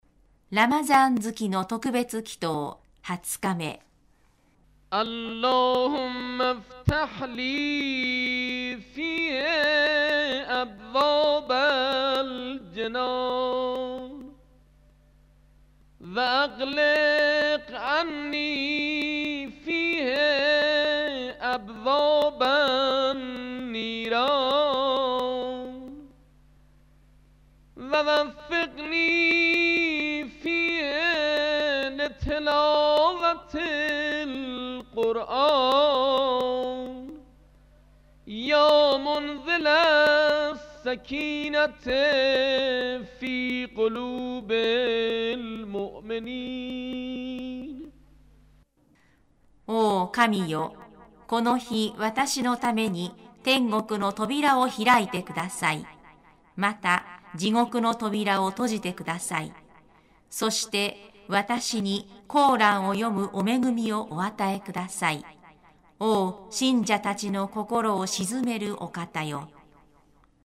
ラマザーン月の特別祈祷 ２０日目（音声）